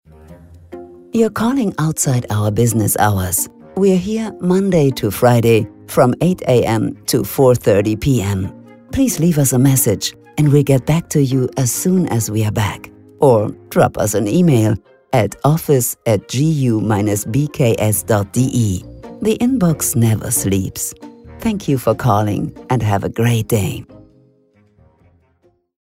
Telefonansagen mit echten Stimmen – keine KI !!!